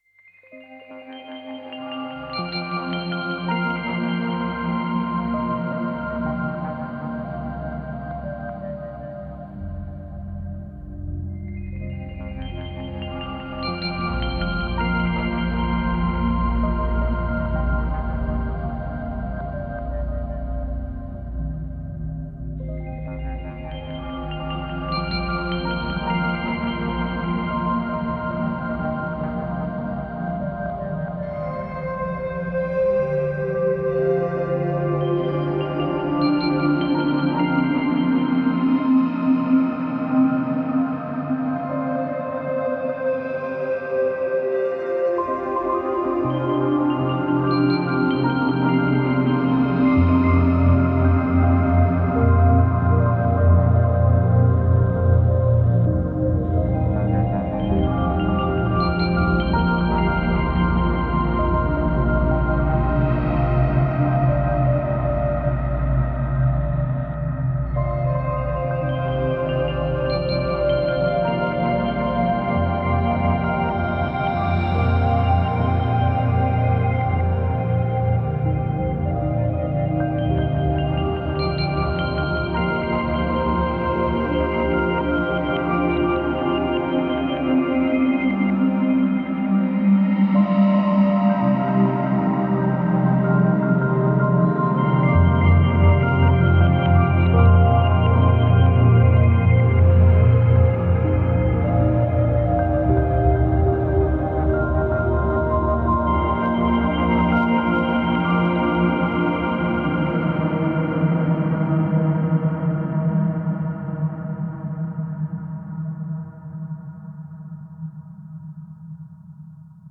A melodious softening of an atmospheric pulse.